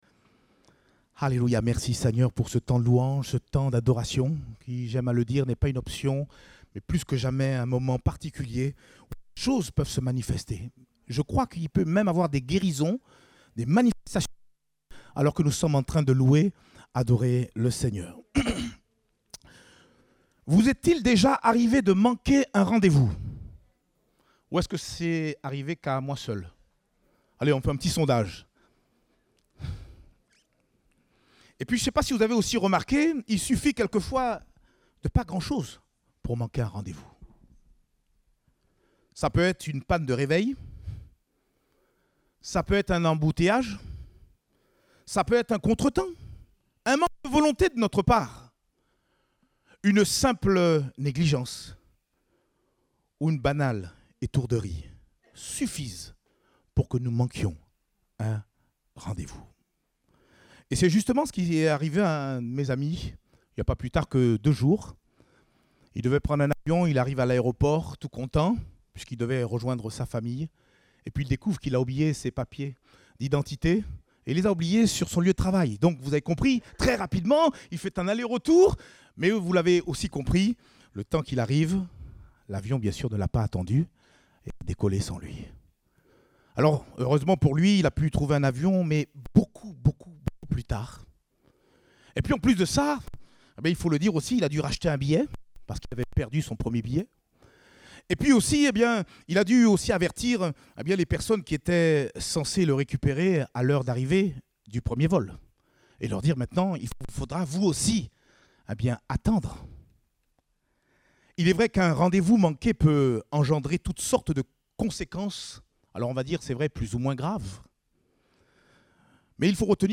Date : 5 décembre 2021 (Culte Dominical)